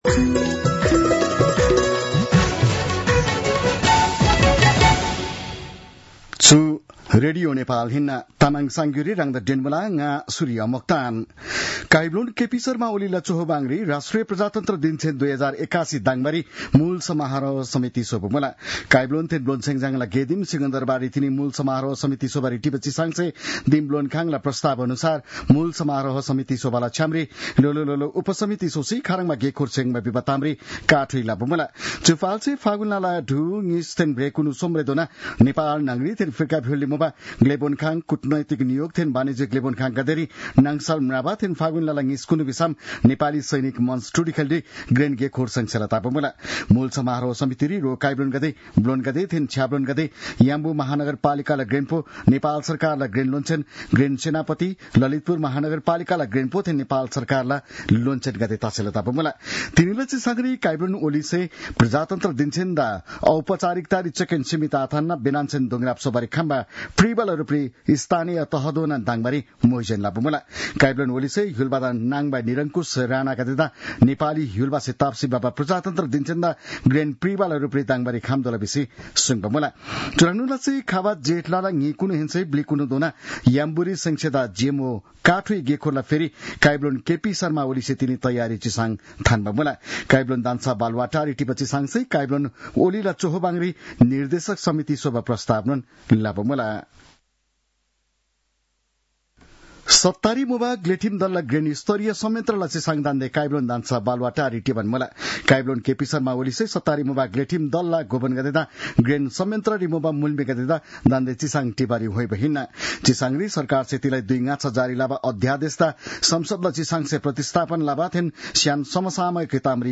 तामाङ भाषाको समाचार : २८ माघ , २०८१